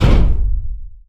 metal_robot_impact_med_step_01.wav